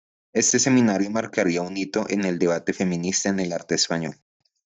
Read more Noun Verb debatir (transitive) to debate (reflexive) to struggle (in a decision) Read more Frequency C1 Hyphenated as de‧ba‧te Pronounced as (IPA) /deˈbate/ Etymology Deverbal from debatir.